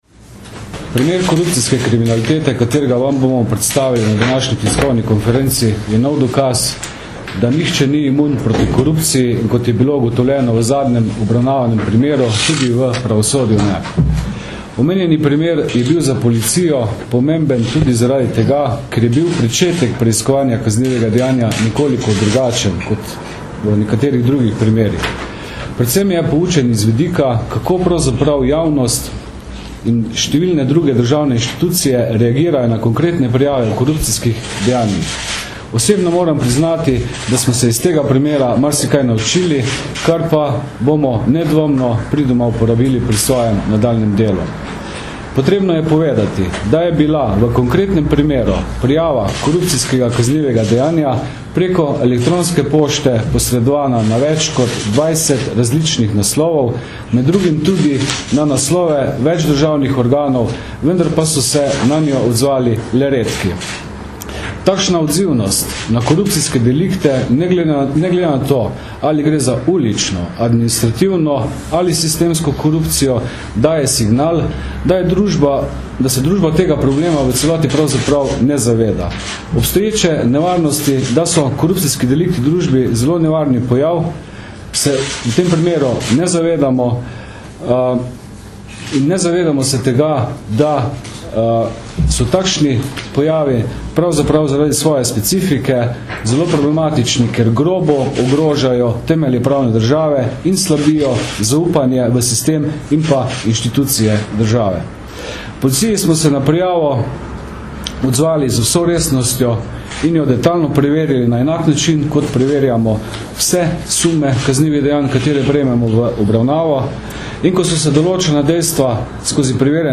Policija - Uspešna preiskava korupcijskega kaznivega dejanja uradne osebe - informacija z novinarske konference